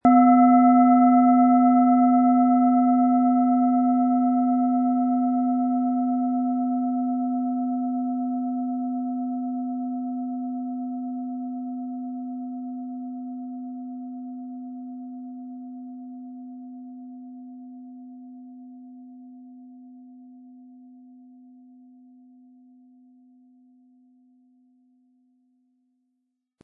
Wie klingt diese tibetische Klangschale mit dem Planetenton Delfin?
Unter dem Artikel-Bild finden Sie den Original-Klang dieser Schale im Audio-Player - Jetzt reinhören.
MaterialBronze